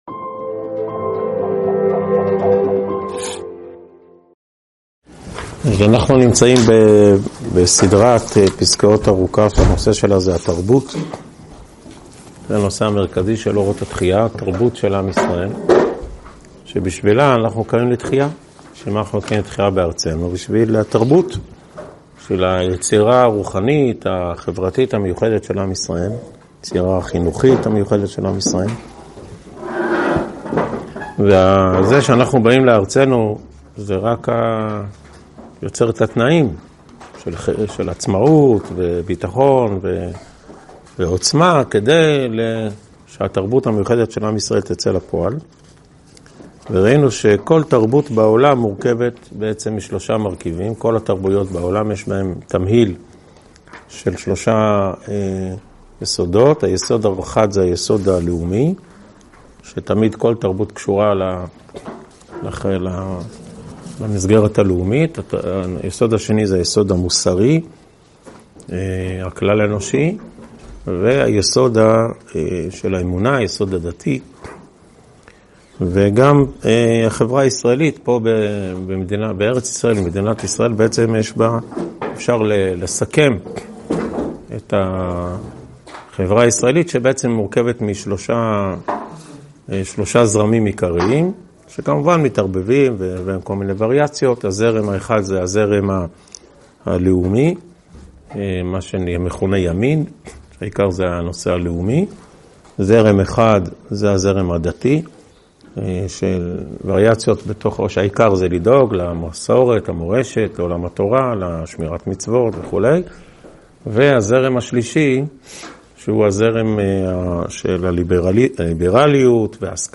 הועבר בישיבת אלון מורה בשנת תשפ"ד.